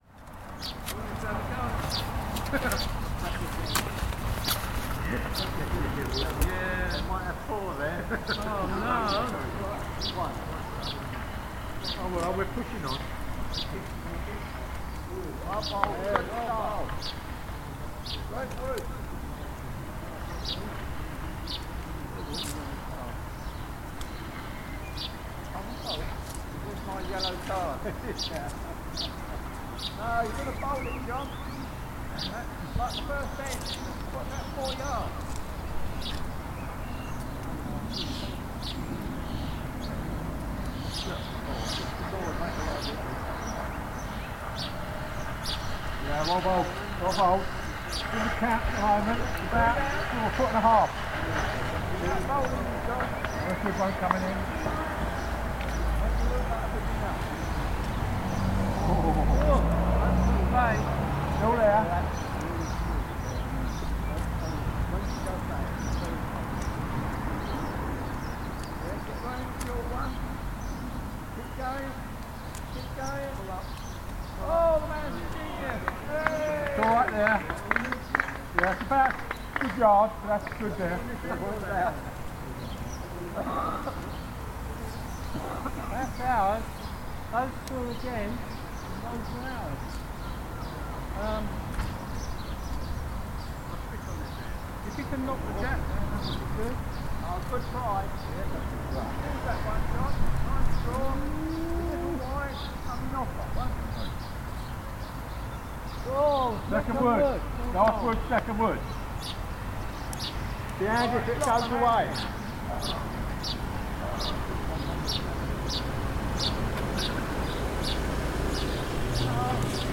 A game of bowls on Westcliff Gardens in Margate.